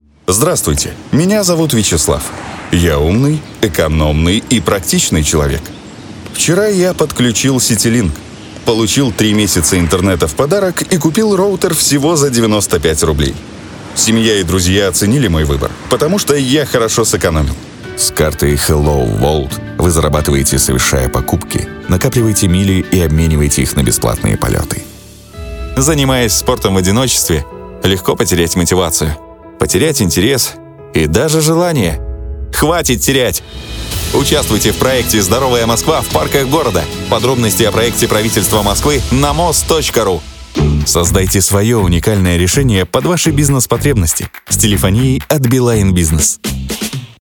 Тракт: Профессиональное звукозаписывающее оборудование, дикторская кабина, запись в сторонней студии